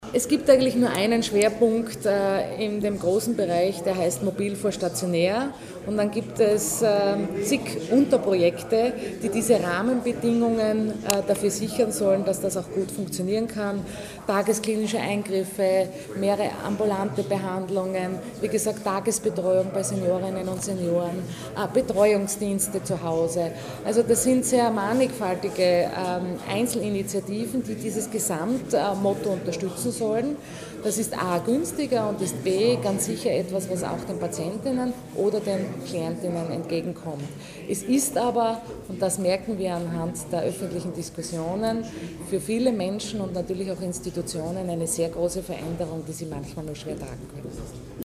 O-Ton: Budgetpräsentation Edlinger-Ploder und Schrittwieser
Oktober 2012).-  Heute (11.10.2012) präsentierten die beiden Landesräte Kristina Edlinger-Ploder und Siegfried Schrittwieser im Medienzentrum Steiermark ihre Ressortdoppelbudgets für die Jahre 2013 und 2014.